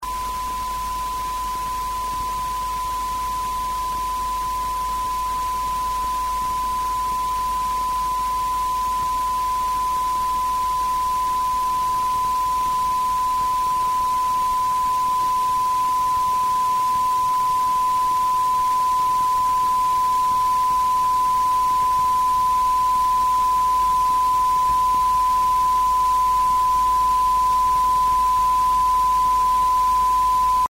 Noise comparison of 2n3819 single fet with opamp version using a NE5534 (left and right channels respectively)